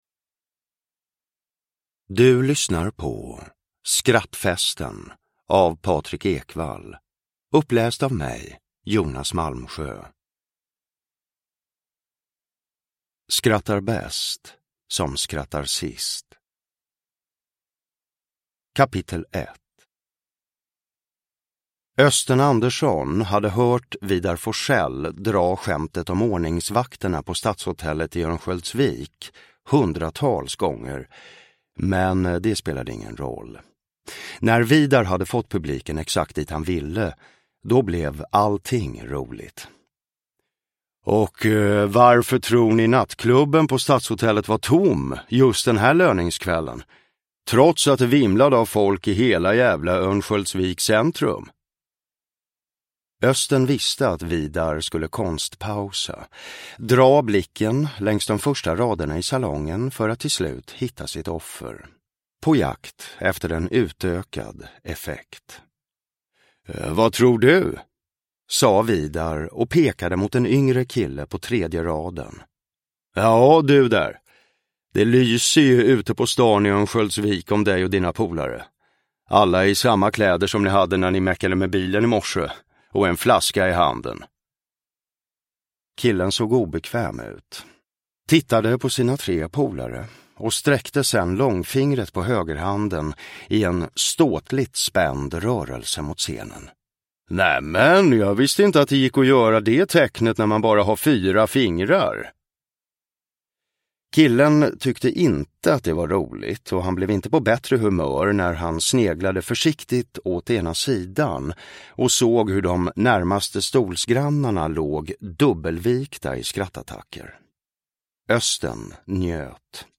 Skrattfesten (ljudbok) av Patrick Ekwall